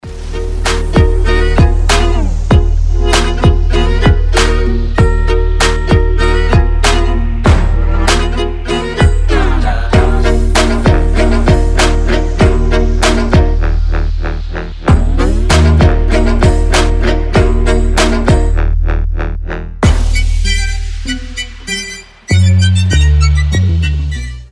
trap , инструментальные
виолончель
скрипка
без слов